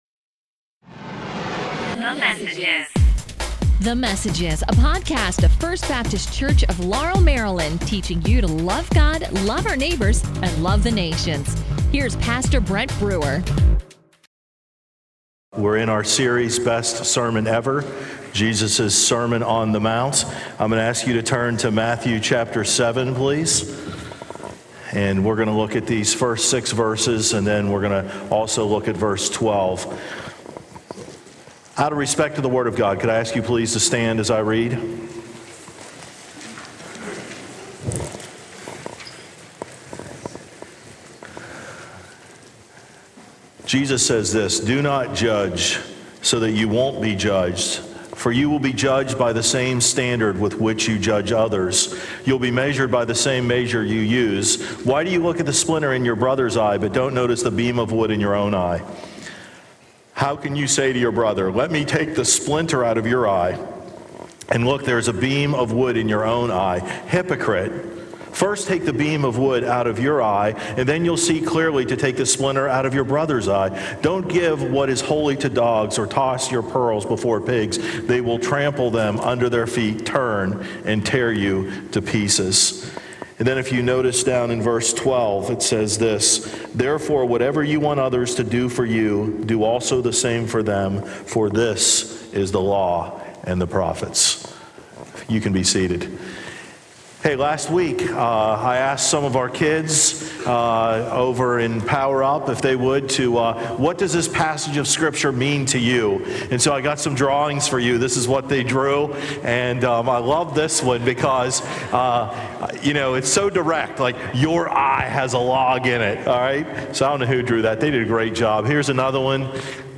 A message from the series "Church in the City."